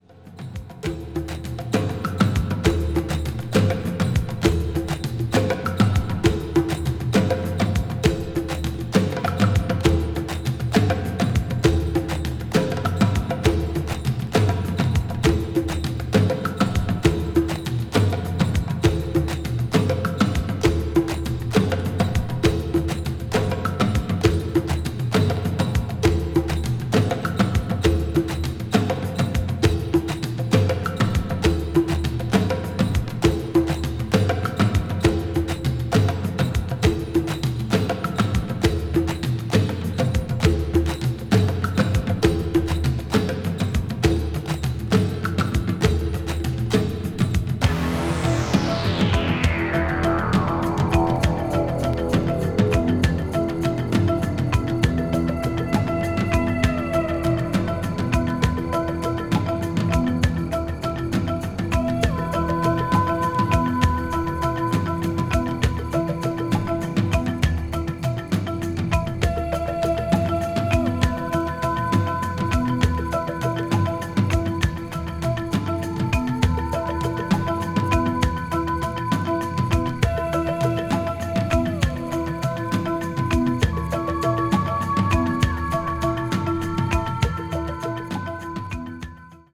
electronic   progressive rock   symphonic rock   synthesizer